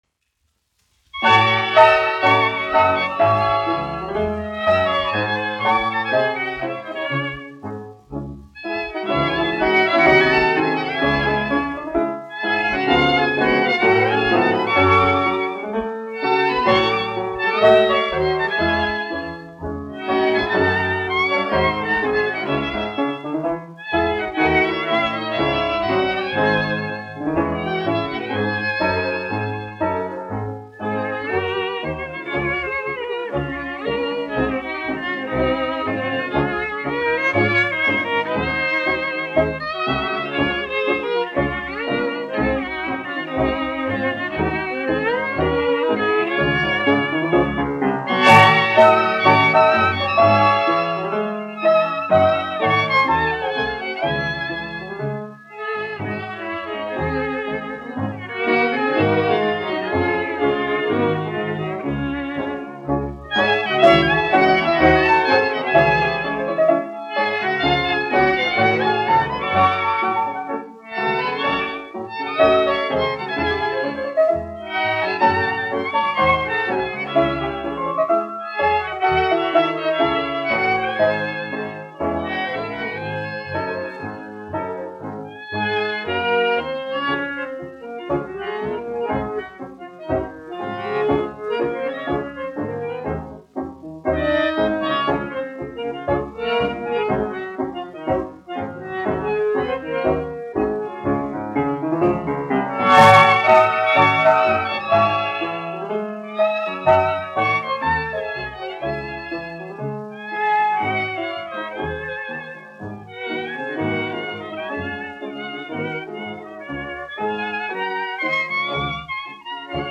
1 skpl. : analogs, 78 apgr/min, mono ; 25 cm
Deju orķestra mūzika
Skaņuplate